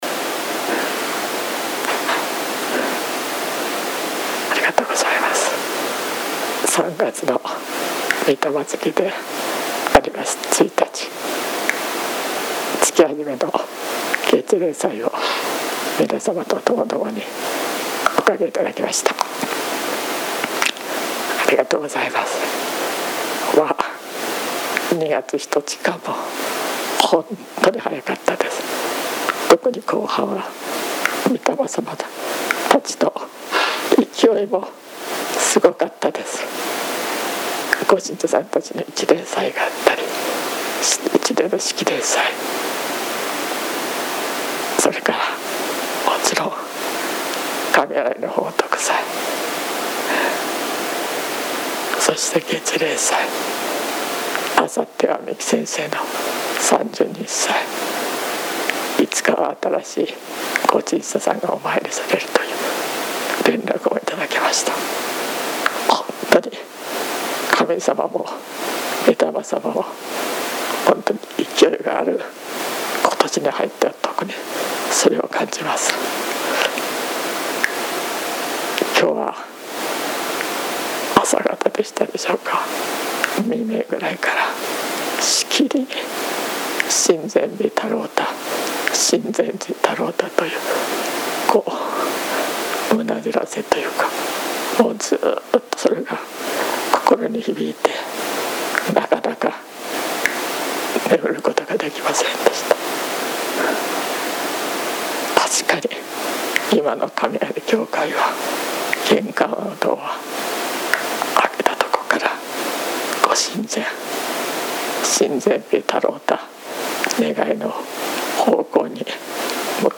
月例祭教話